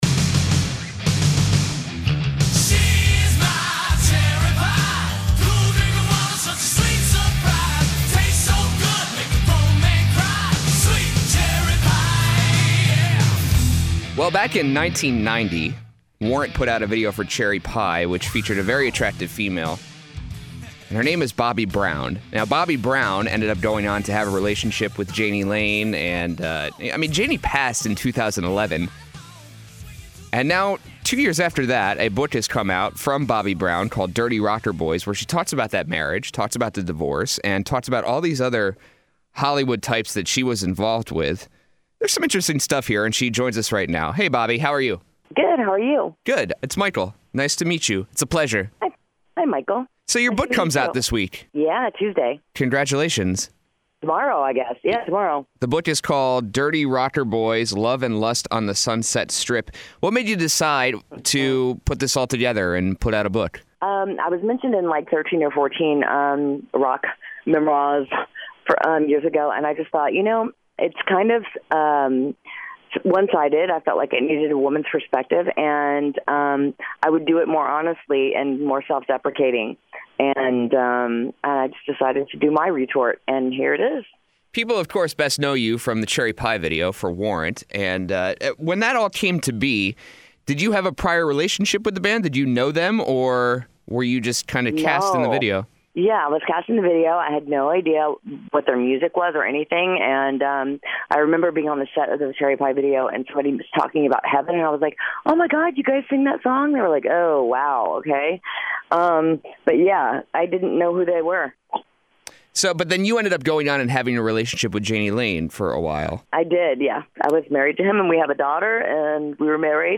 Interview: Warrant Video Star Bobbie Brown
bobbie-brown-interview-web.mp3